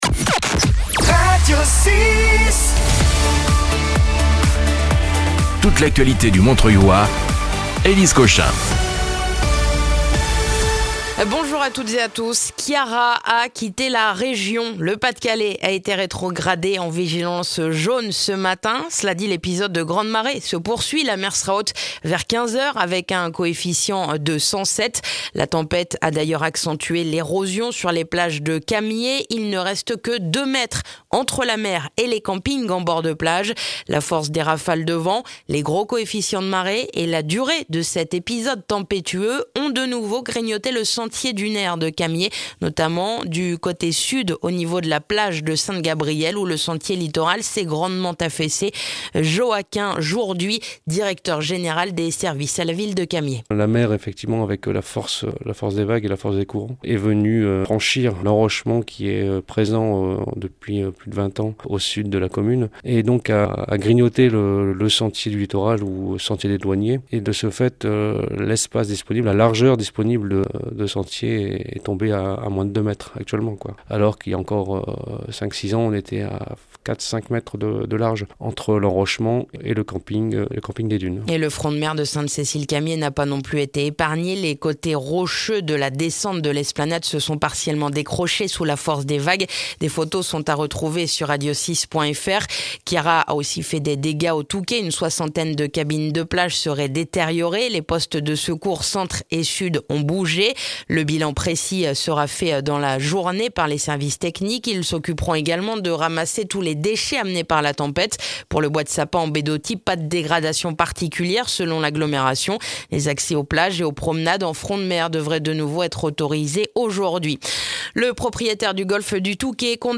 Le journal du mercredi 12 février dans le montreuillois